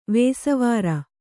♪ vēsavāra